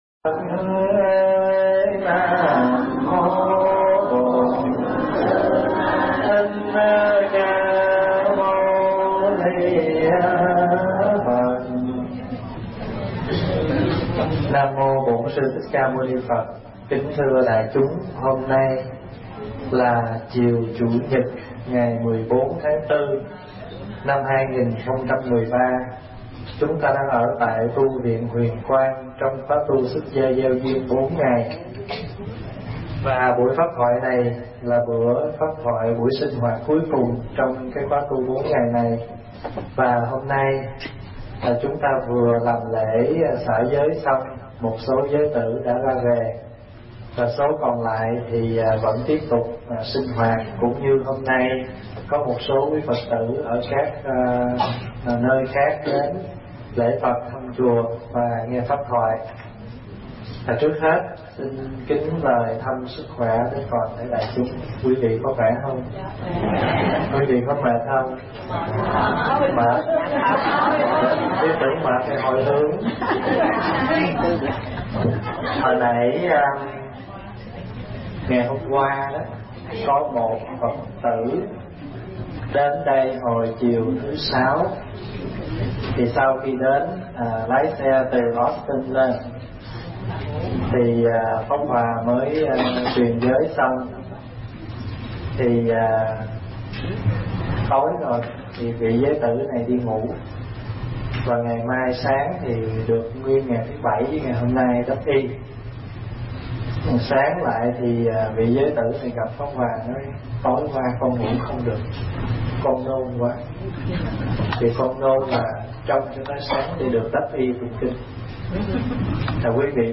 Tải mp3 Thuyết Giảng Năm Đức Sadi 2
thuyết giảng tại Tu Viện Huyền Quang, ngày 14 tháng 4 năm 2013